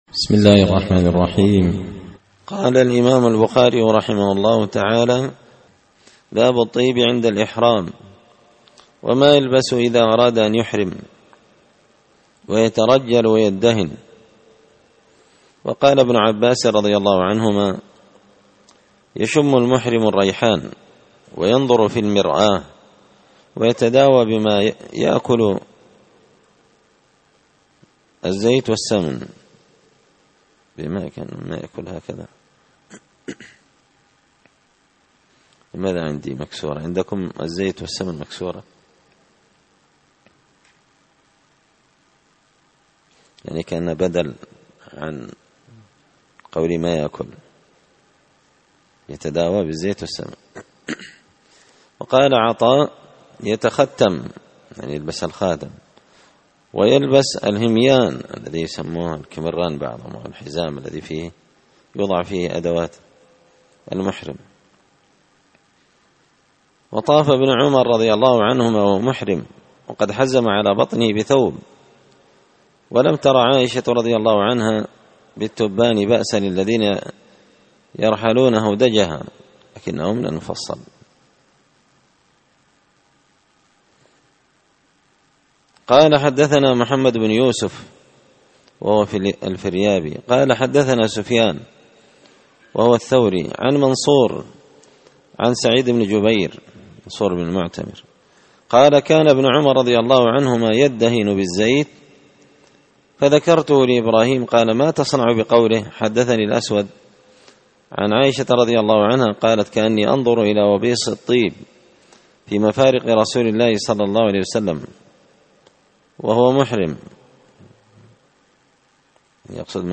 كتاب الحج من شرح صحيح البخاري – الدرس 14
دار الحديث بمسجد الفرقان ـ قشن ـ المهرة ـ اليمن